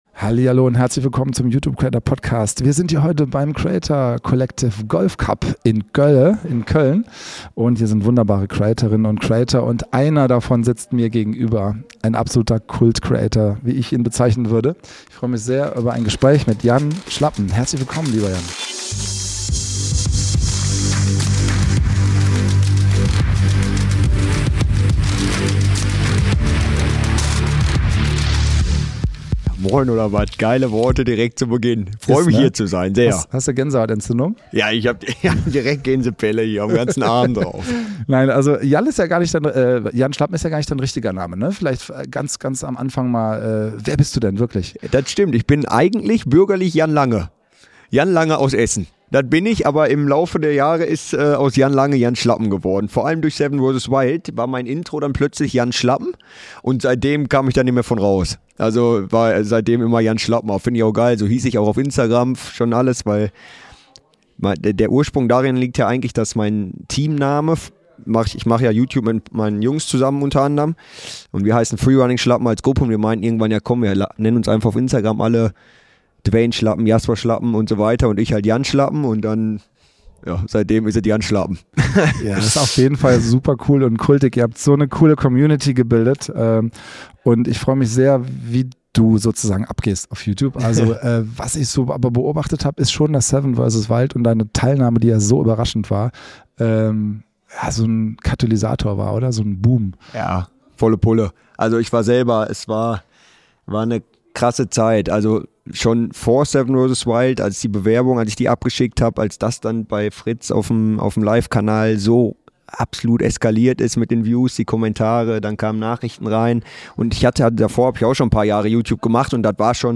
In dieser Episode des YouTube Creator Podcasts "Meine YouTube Story" melden wir uns erneut vom Creator Golfcup in Köln.